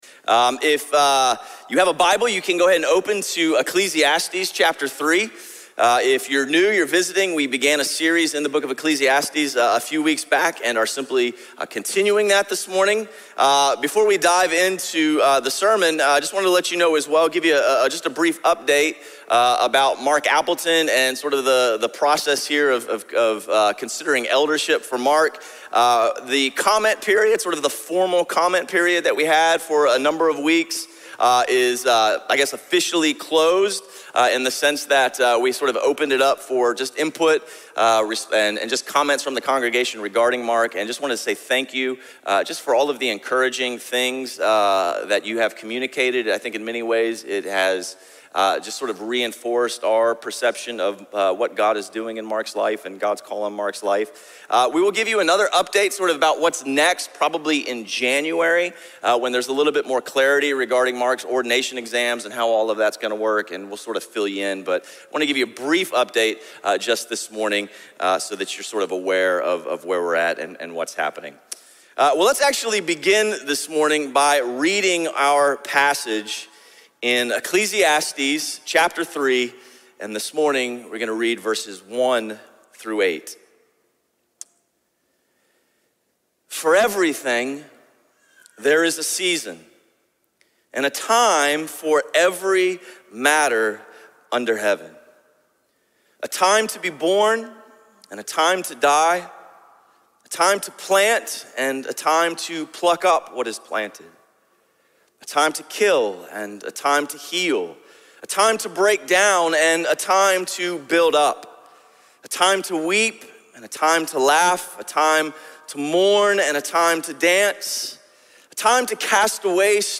Sermon series through the book of Ecclesiastes.